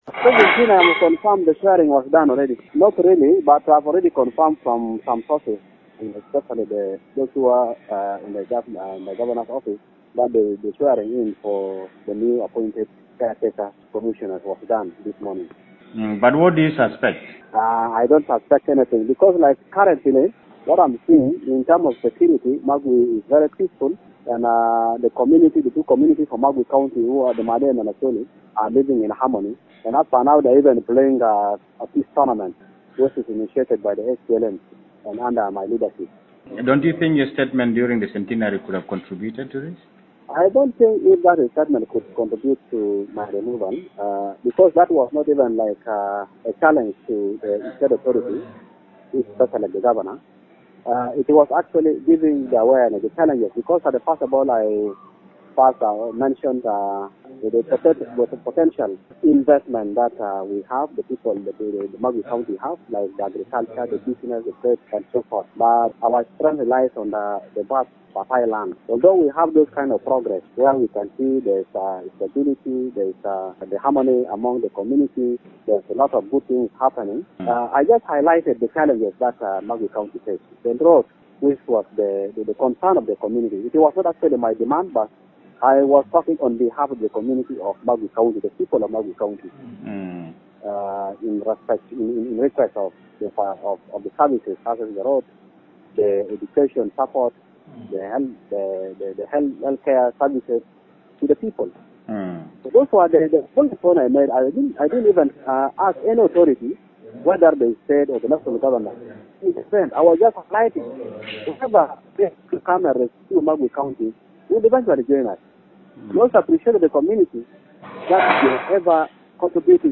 In an exclusive interview with Eye Radio today, Pole Pole addressed the circumstances surrounding his suspension and offered words of reassurance to the local community.